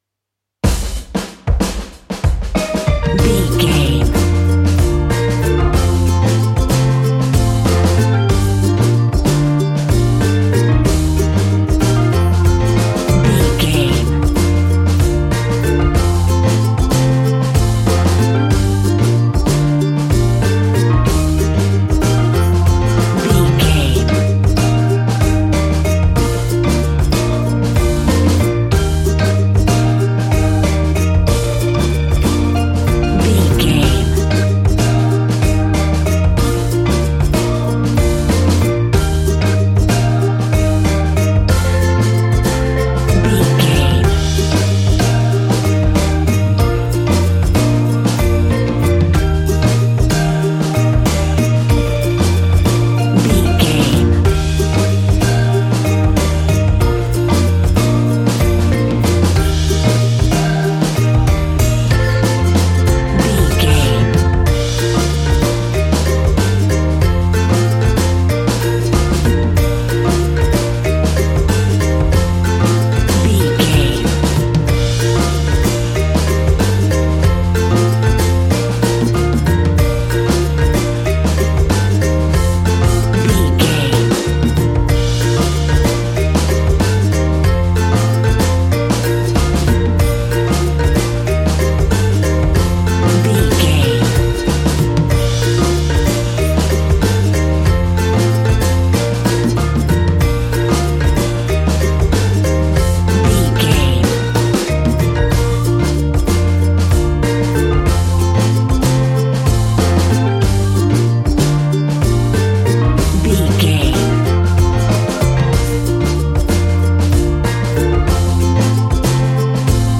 Ionian/Major
B♭
steelpan
drums
brass
guitar